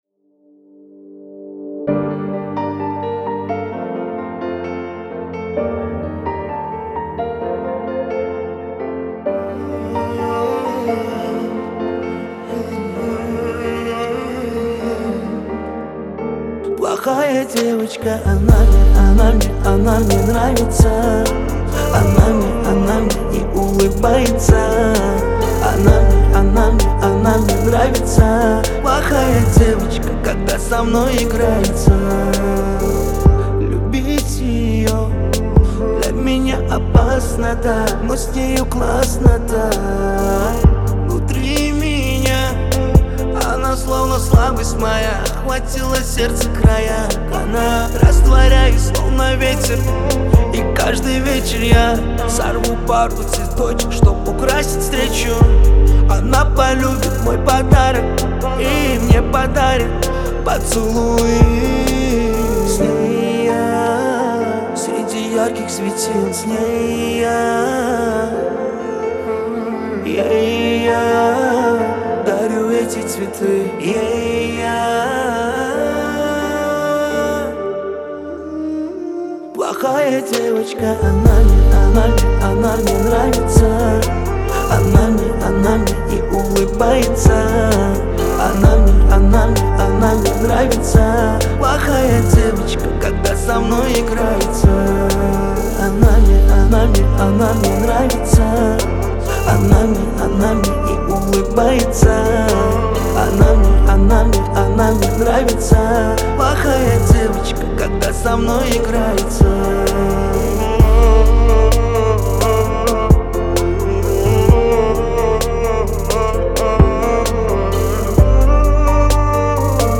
это энергичный трек в жанре поп с элементами R&B